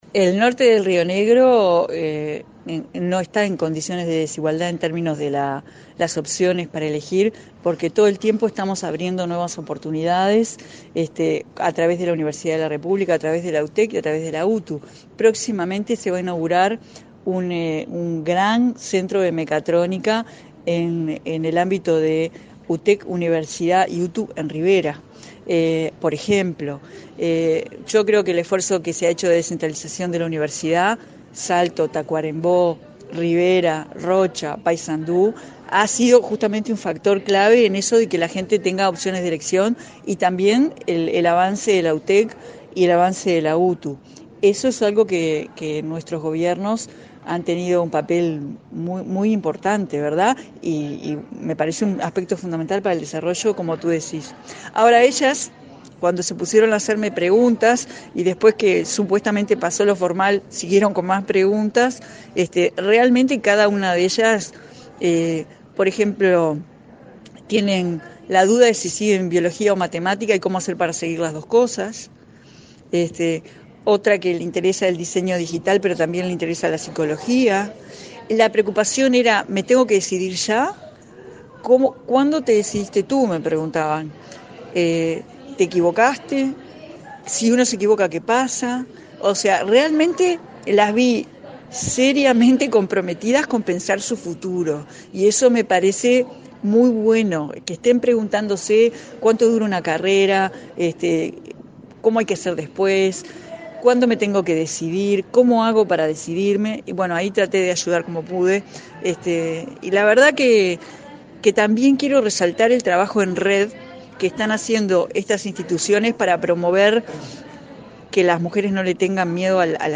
“El norte del río Negro no está en condiciones de desigualdad en opciones para elegir (qué estudiar) porque todo el tiempo estamos abriendo oportunidades”, afirmó la ministra de Industria, Carolina Cosse, al destacar que UTU, UTEC y Udelar inaugurarán un centro de mecatrónica en Rivera. La descentralización educativa es fundamental, agregó, tras participar en un encuentro con adolescentes de Lavalleja, Rocha y Maldonado.